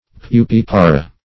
Search Result for " pupipara" : The Collaborative International Dictionary of English v.0.48: Pupipara \Pu*pip"a*ra\, n. pl.
pupipara.mp3